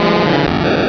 Cri de Magicarpe dans Pokémon Rubis et Saphir.